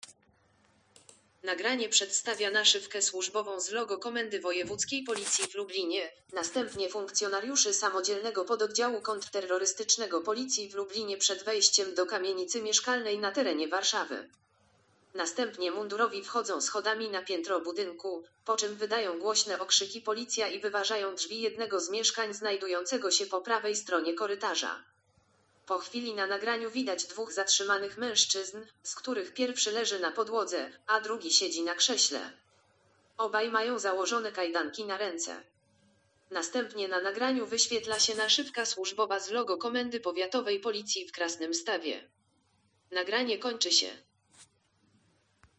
Nagranie audio Audiodeskrypcja_filmu_wejscie_silowe_do_mieszkania.mp3
Opis nagrania: Audiodeskrypcja filmu wejście siłowe do mieszkania